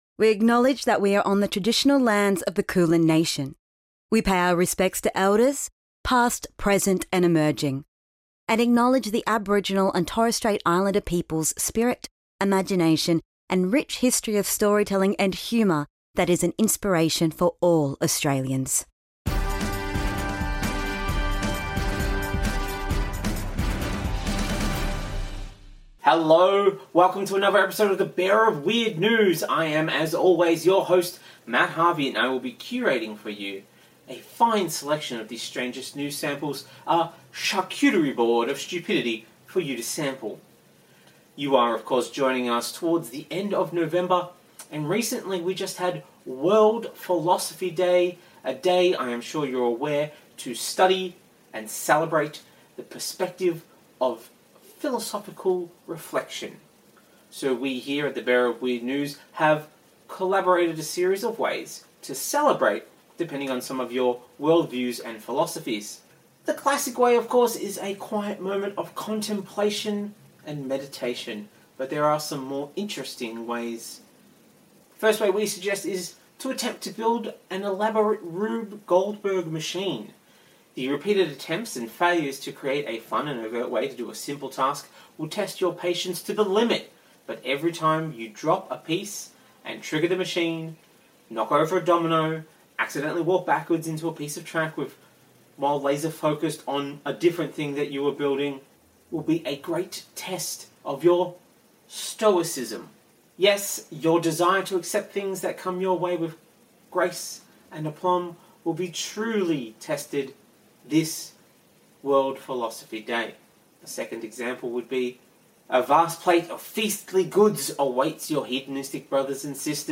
Australian news round-up